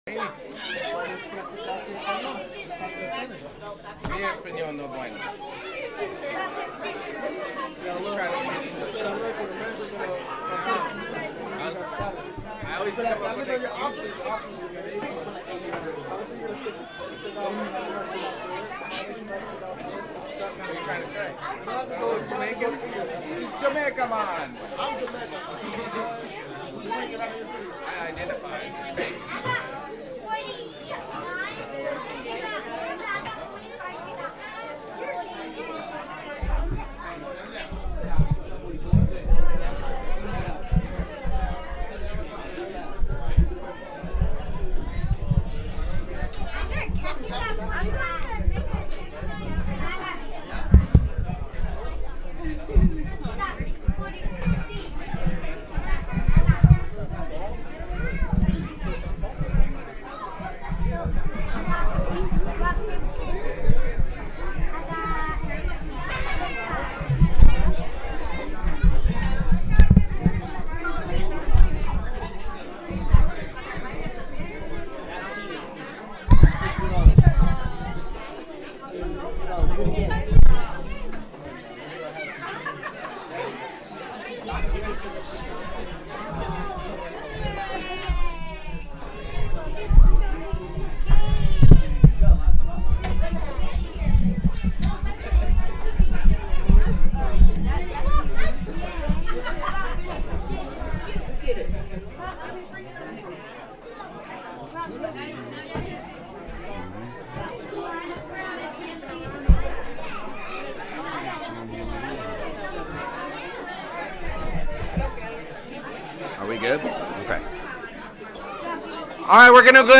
Soulwinning Seminar